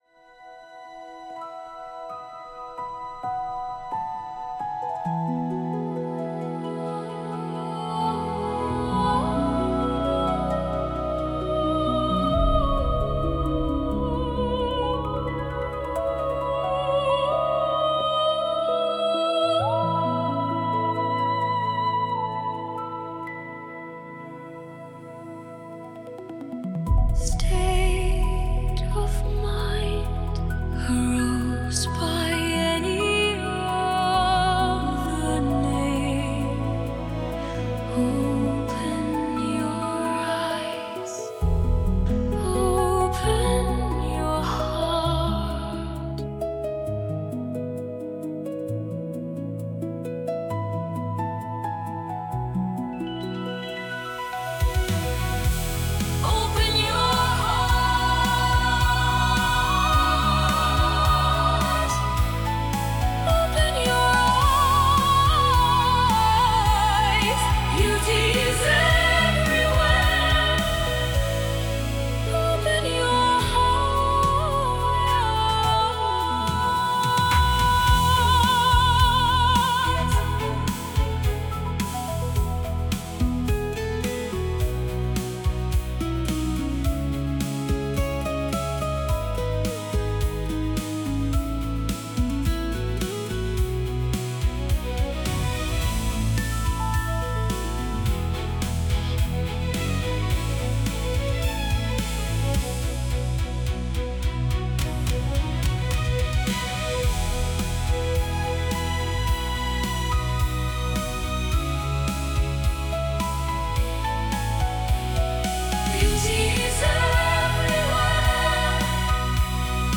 …a simplistic ballad againts “hate”, as an emotional attitude in our hearts, we simply assume hate is an emotion often rooted in fear, mistrust, powerlessness, or vulnerability, but it seems our sciences still doesn’t “know” exactly what hate is.